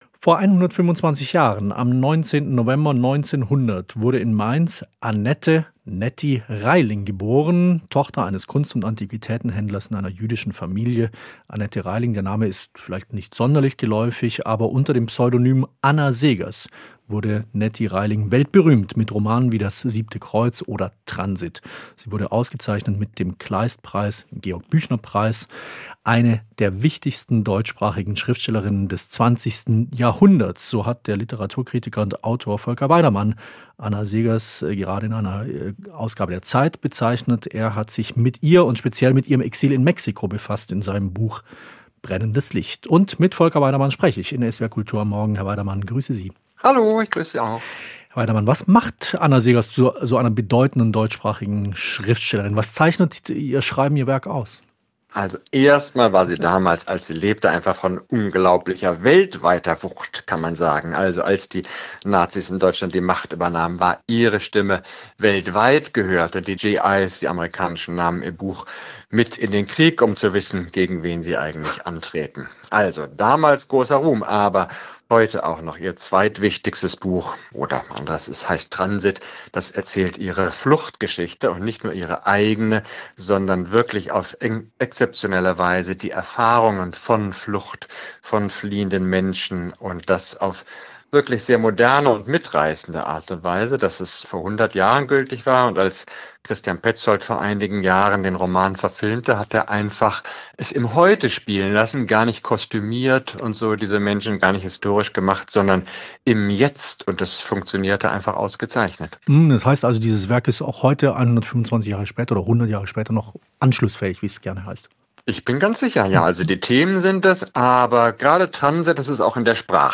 Gespräch
Das Interview führte